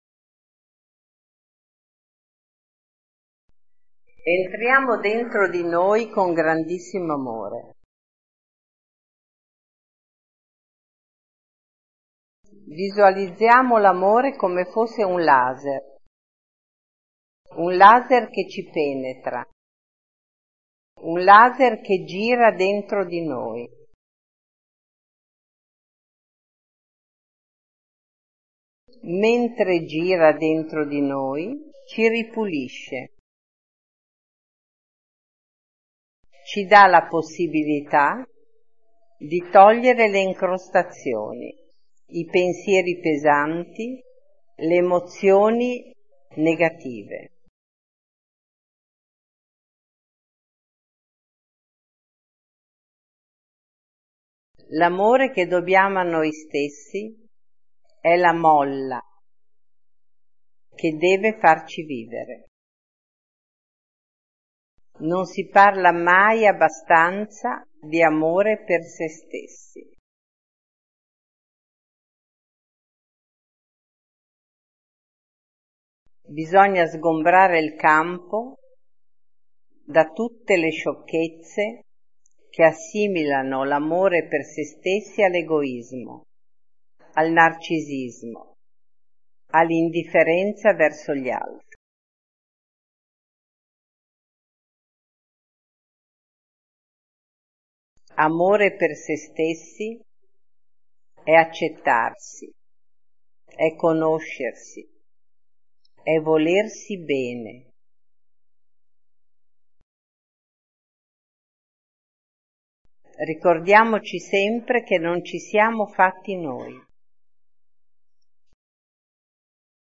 Siamo quello che Siamo – meditazione
Siamo-quello-che-siamo-meditazione.mp3